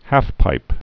(hăfpīp, häf-)